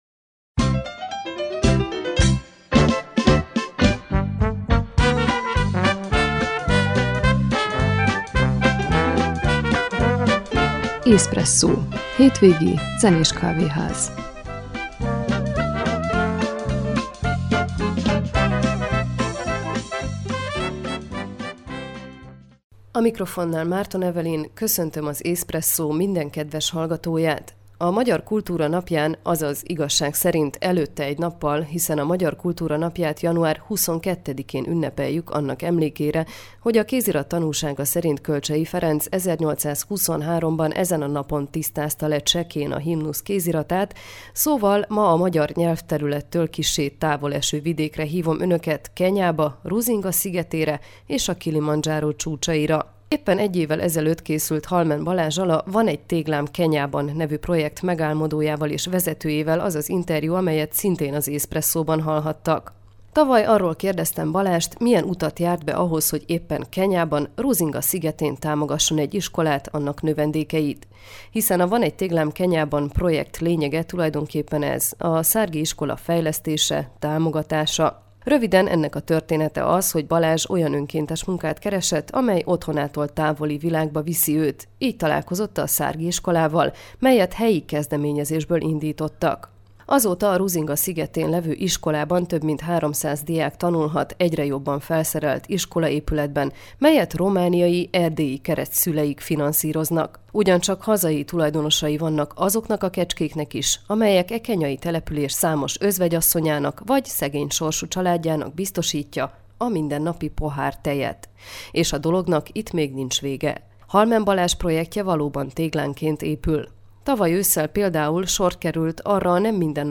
azt a most következő beszélgetésből tudhatják meg